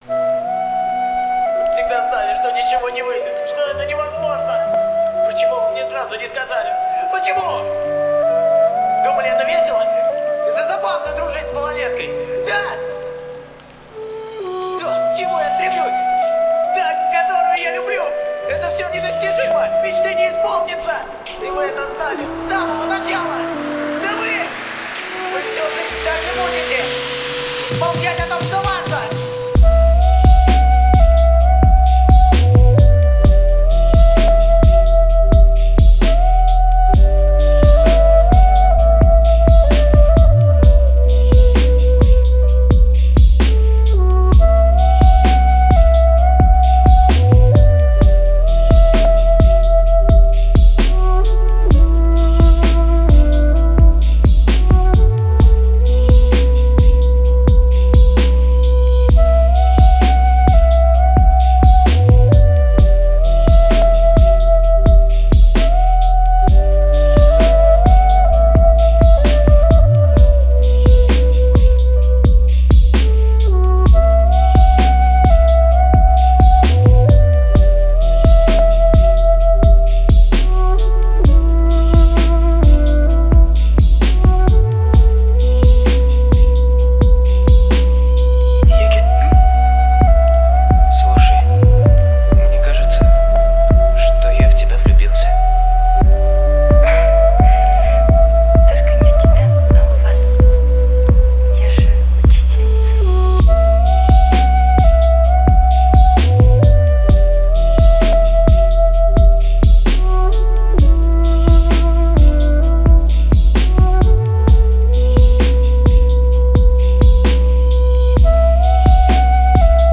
Жанр: Acoustic & Vocal